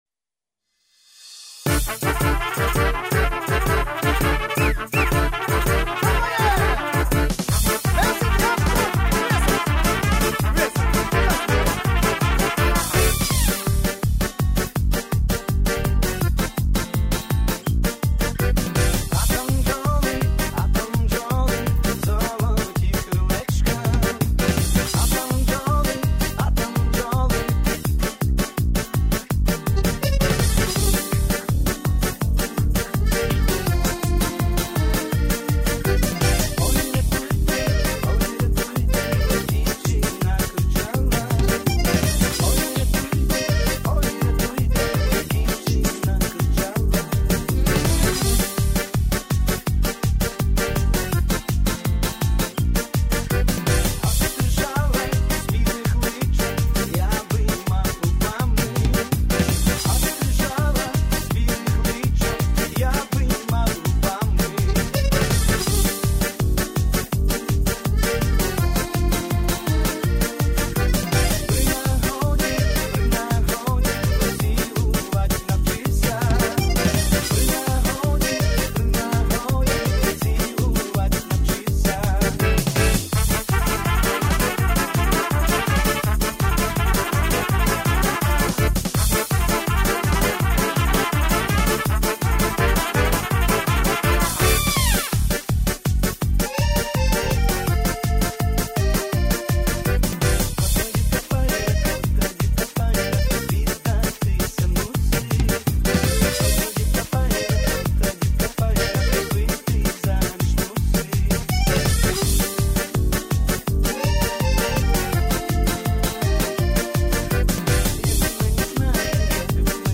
Мінусівка.
з бек-вокалом